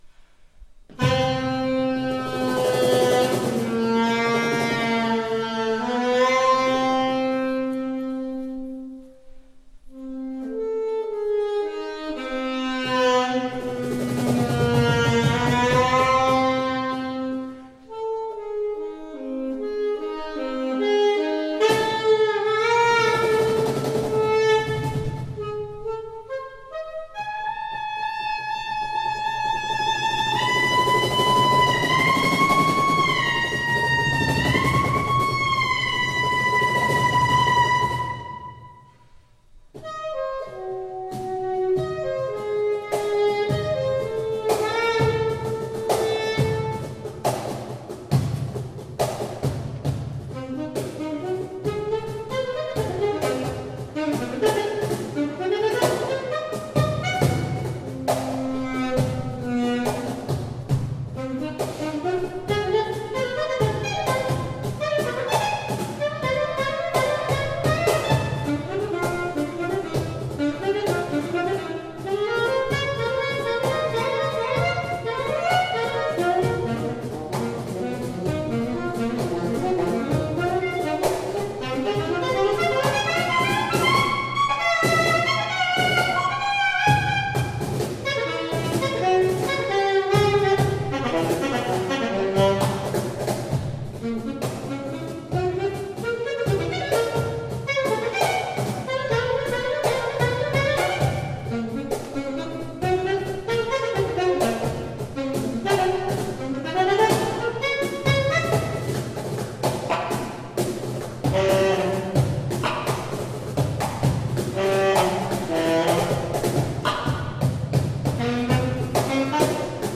Voicing: Chamber Duet